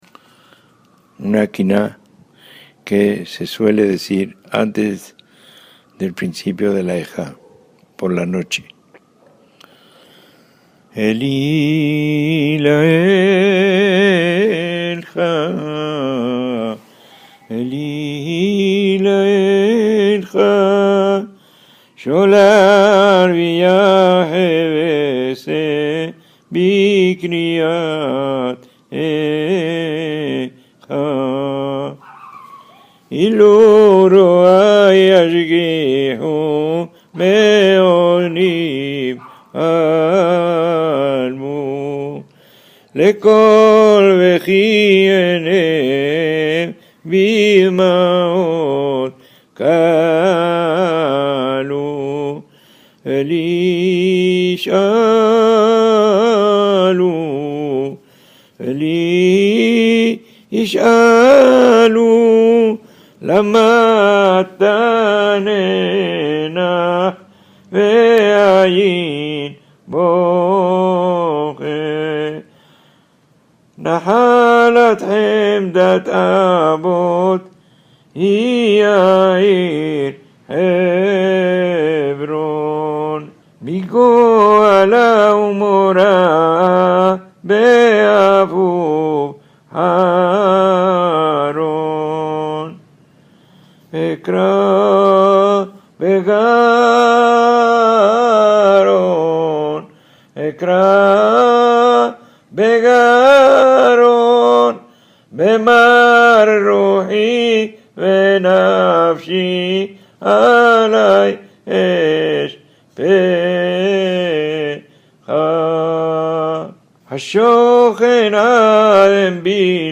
Kinot (oraciones fúnebres)
En este caso escuchamos estas endechas